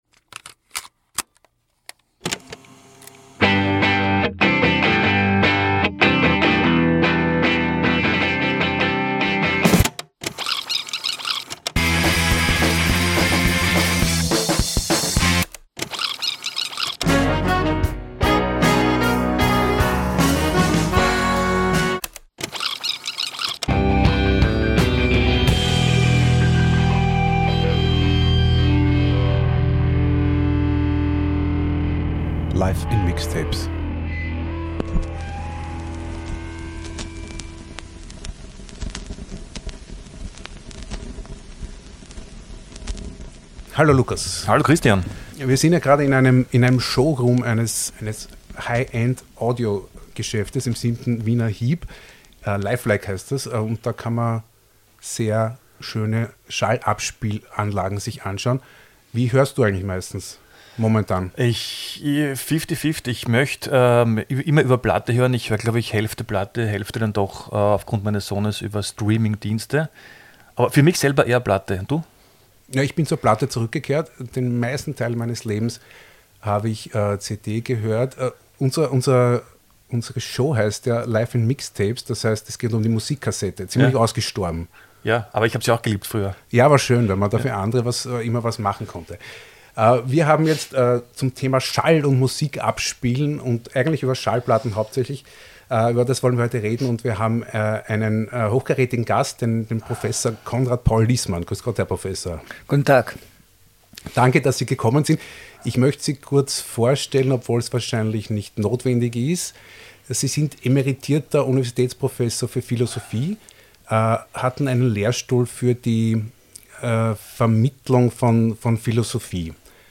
Wir haben uns mit ihm im Showroom des Wiener HiFi-Händlers Lifelike getroffen, gemeinsam Musik gehört und über Musikwiedergabegeräte im Besonderen und seine musikalische Biographie im Allgemeinen gesprochen. Heute, im ersten Teil des Gesprächs, geht es um die ersten Platten seiner Kindheit, musikalische Weggabelungen, Adornos Verachtung von Populärmusik, Nick Hornby und den Soundtrack der 68er Bewegung.